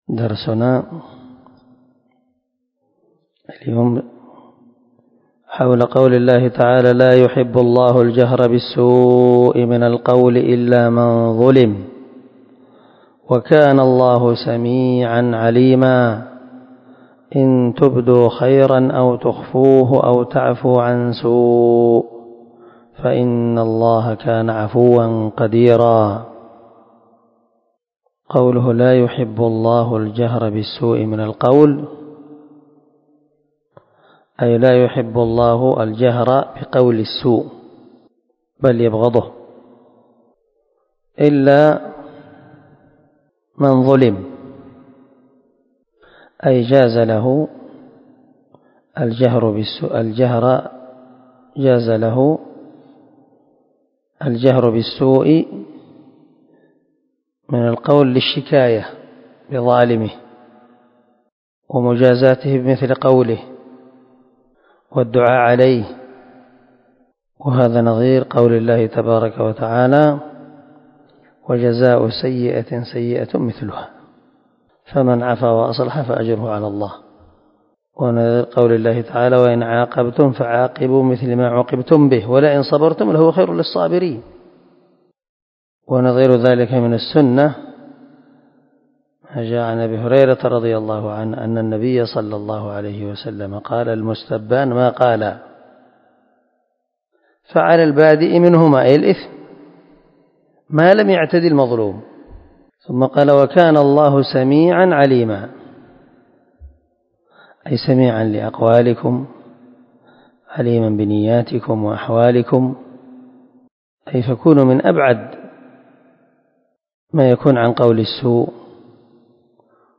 322الدرس 90 تفسير آية ( 148-149 ) من سورة النساء من تفسير القران الكريم مع قراءة لتفسير السعدي
دار الحديث- المَحاوِلة- الصبيحة.